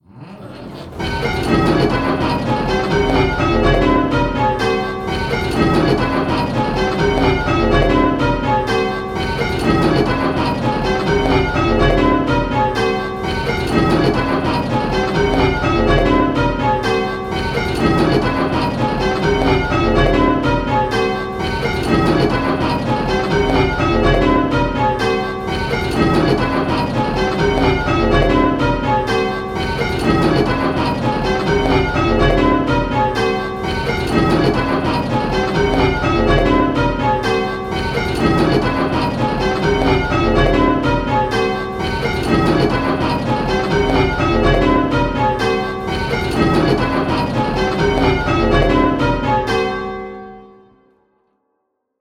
Hearing 8 bells...
We know that hearing specific bells can be difficult, so in these clips we have kept it very simple… in each clip 1 bell is ringing early… and consistently at both hand and backstroke.
Click the play button and listen to the rhythm of the bells…. the rhythm will sound a bit lumpy!
Rounds-8-5E.m4a